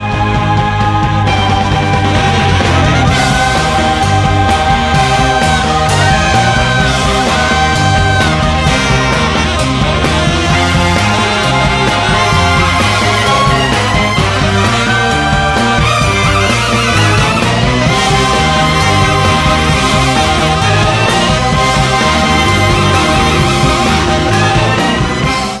• Качество: 192, Stereo
Там звучит такая же забавная музыка.